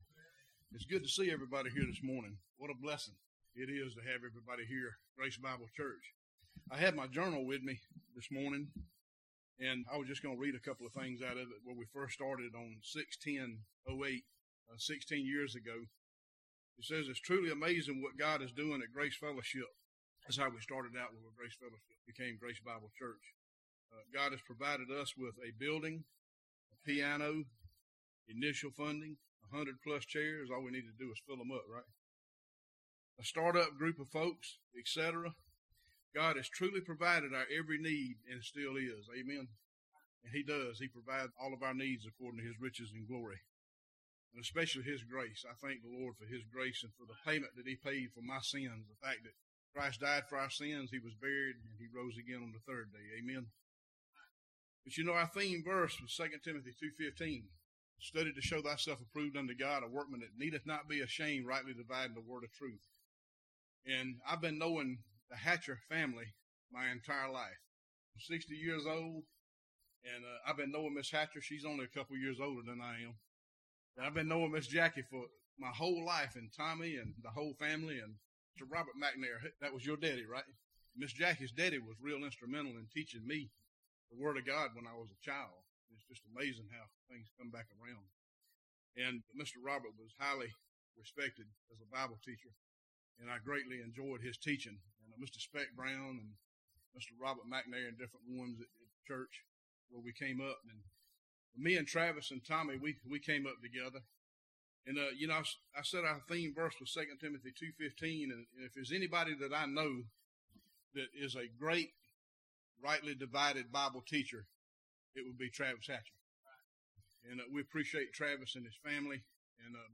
Homecoming Sermon